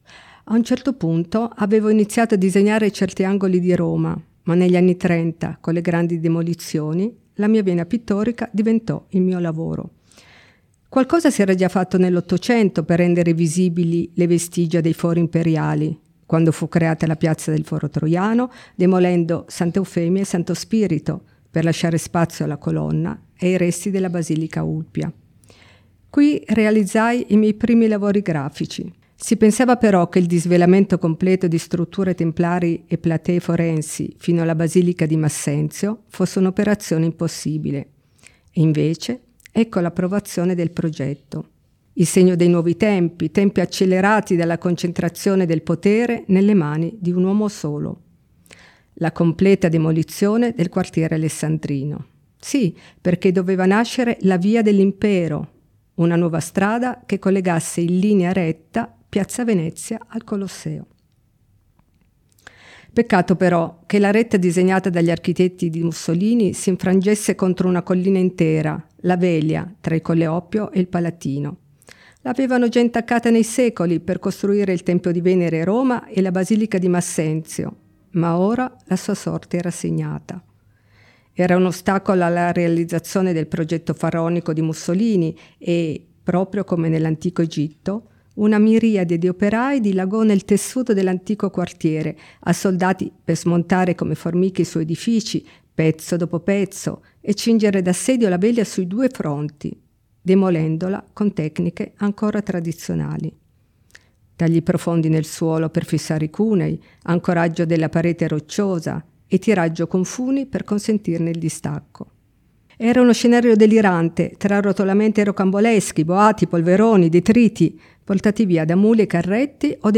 • approfondimenti audio, con il racconto in prima persona di Maria Barosso e le audiodescrizioni delle opere selezionate
Storytelling Maria Barosso: